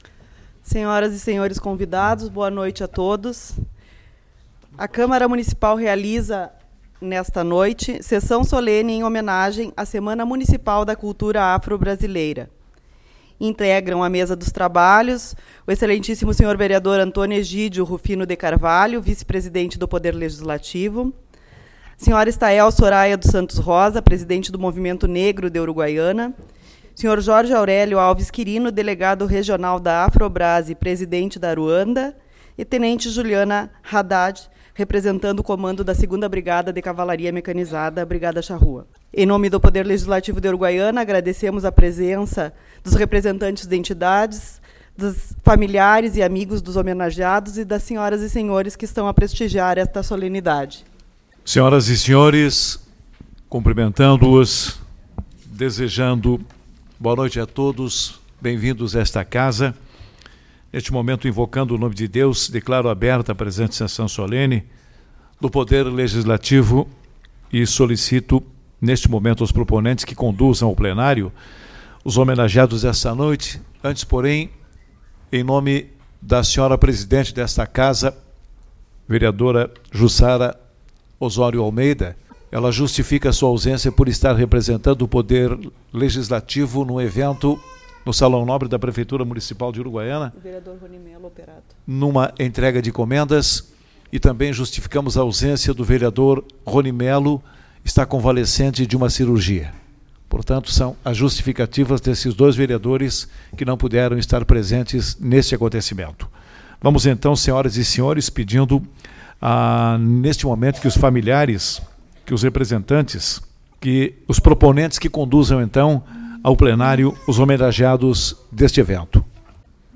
20/11 - Sessão Solene-Consciência Negra